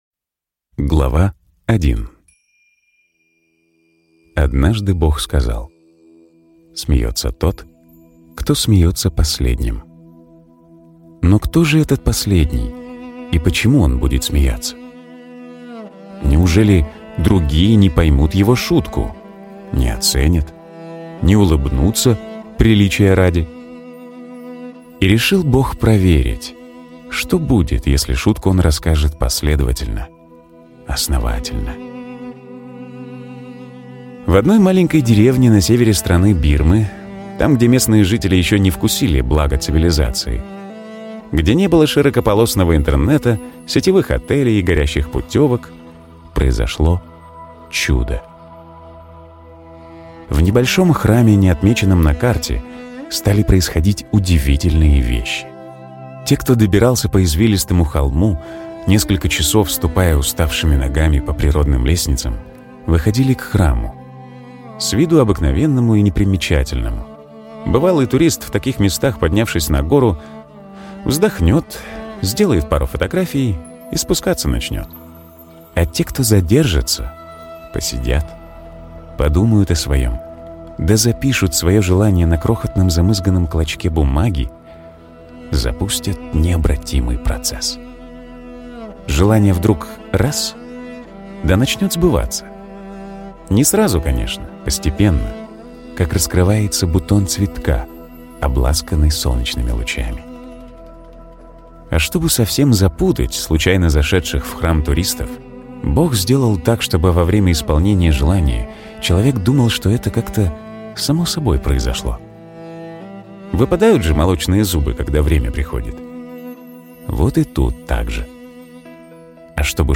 Аудиокнига «Место, где сбываются мечты».